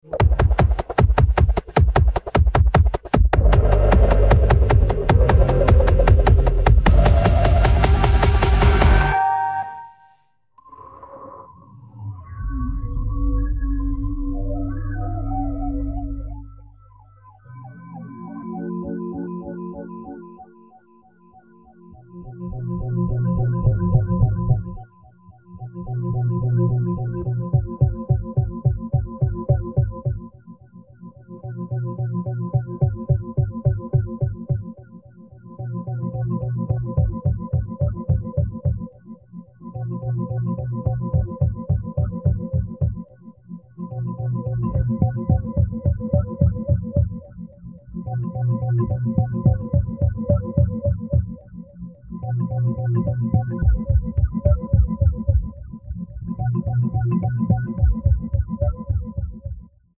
制作一首只使用钢琴和小提琴演奏的纯音乐用于结尾谢幕